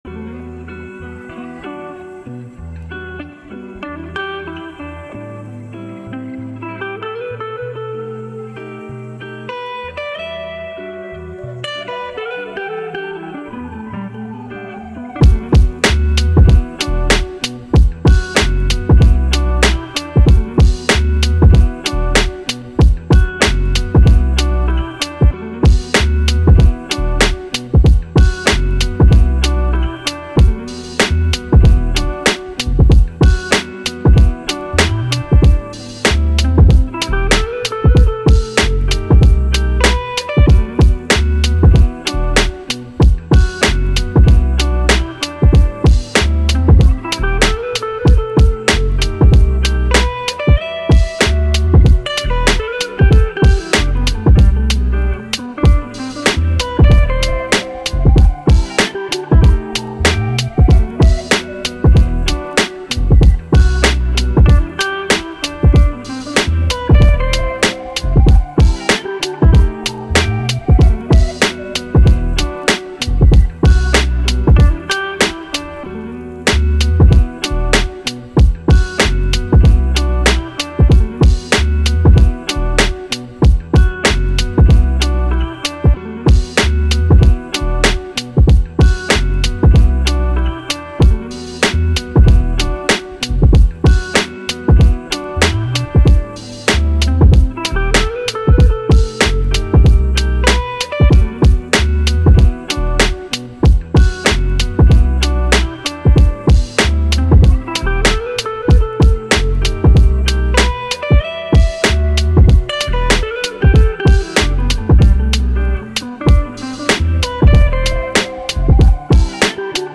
Hip Hop, Rap
E Minor